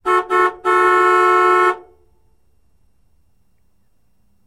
Звук эвакуаторщика сигналит в свой гудок